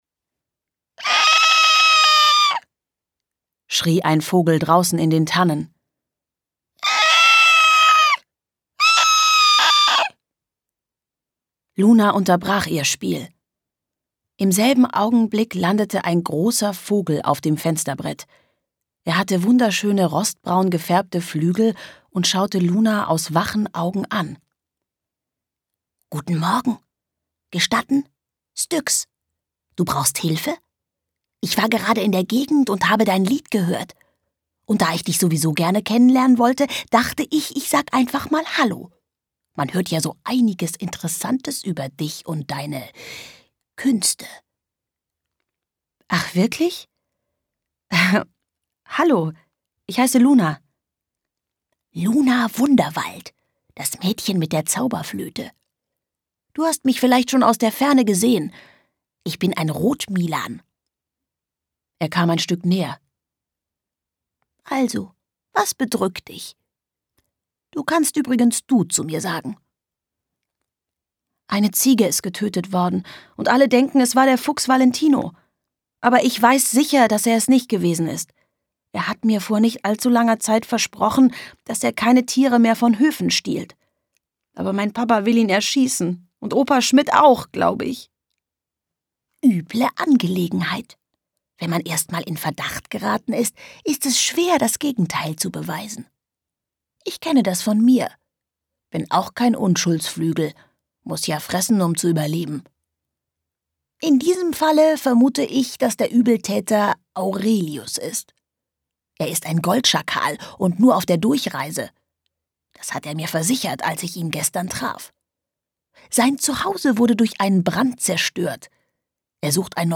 Hörbuch: Luna Wunderwald.